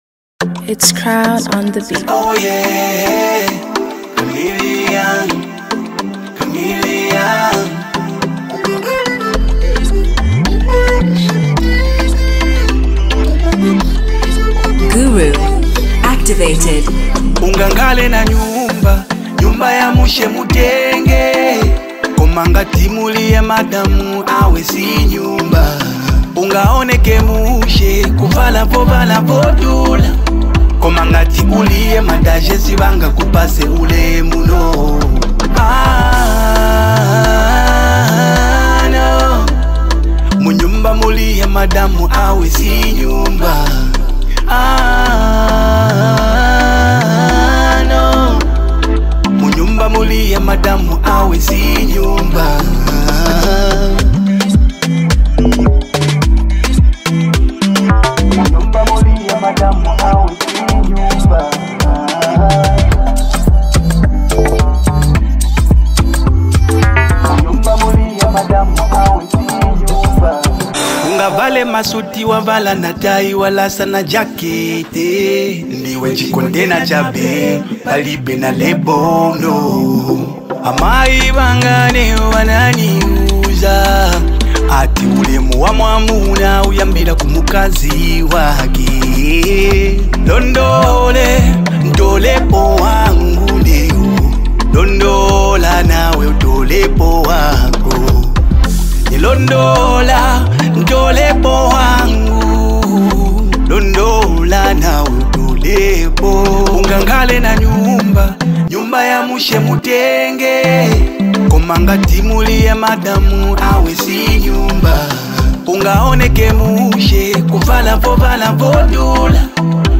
R&B
Known for his smooth vocals and emotional depth
polished and contemporary sound